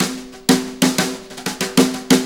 Brushes Fill 69-04.wav